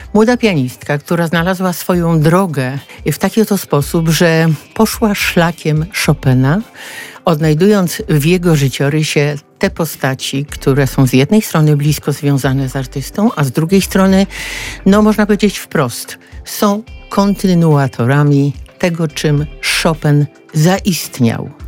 mówi gość Radia Lublin